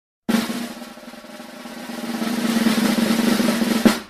Drum Roll Sound Effect